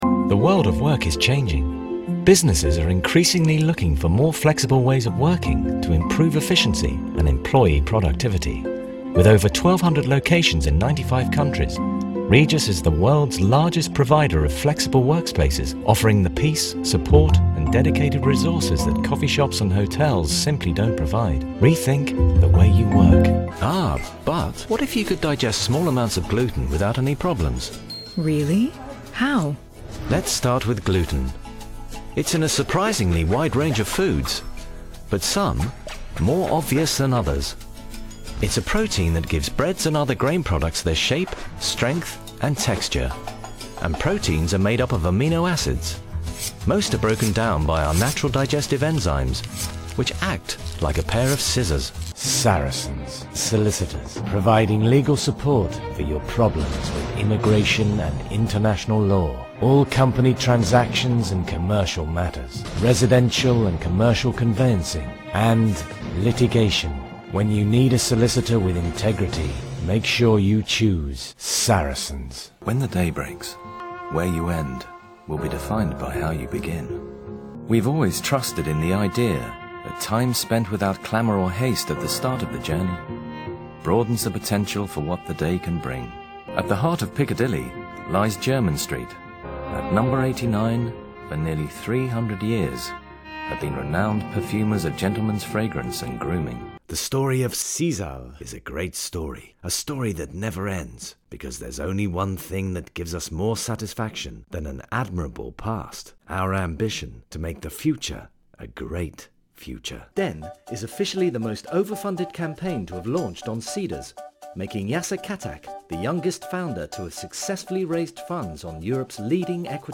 Male
English (British)
Adult (30-50)
He has an assured vocal delivery, with a clear, professional edge.
Corporate
All our voice actors have professional broadcast quality recording studios.